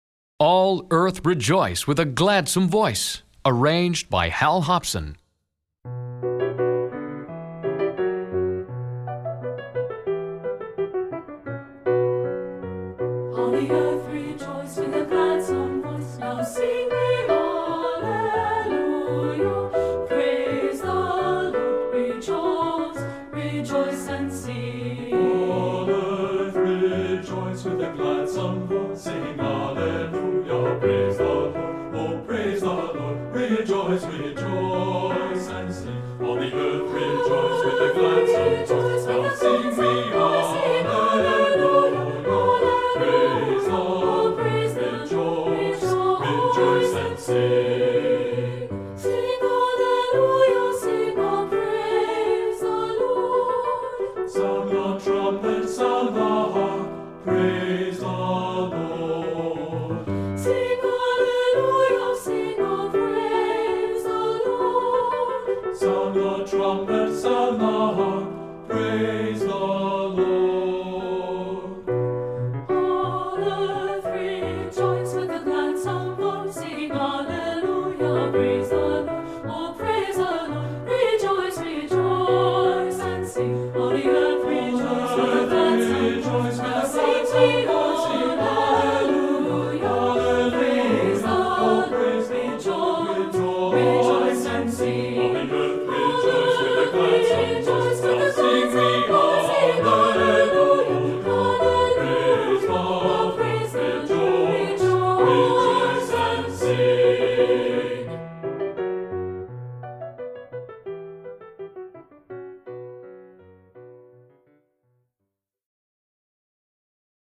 Voicing: 2-Part Mixed